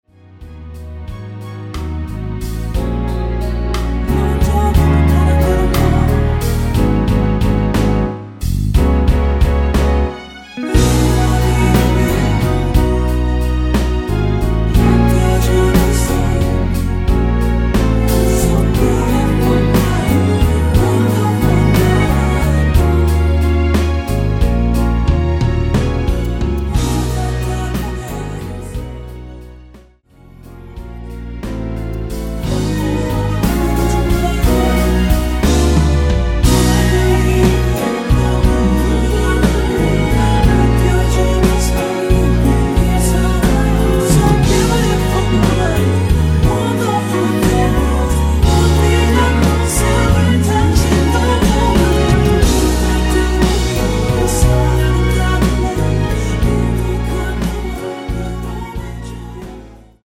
(-2)코러스 포함된 MR 입니다.
F#
앞부분30초, 뒷부분30초씩 편집해서 올려 드리고 있습니다.